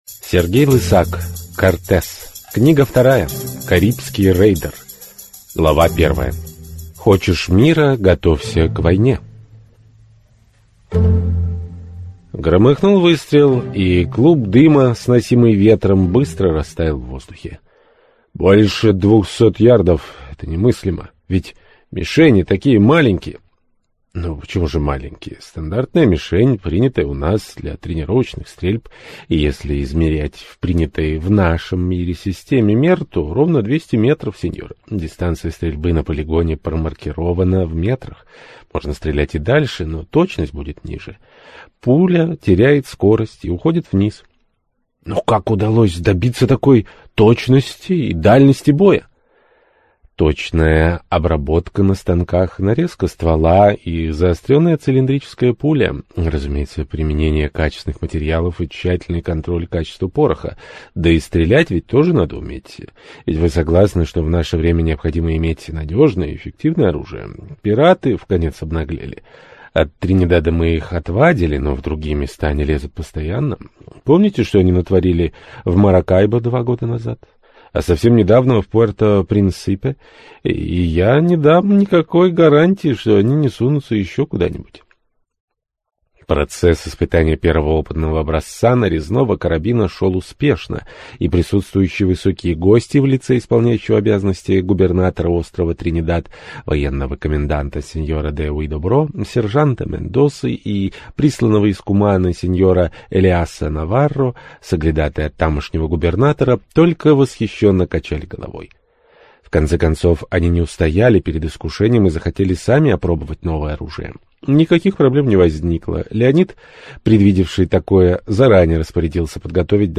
Аудиокнига Карибский рейдер | Библиотека аудиокниг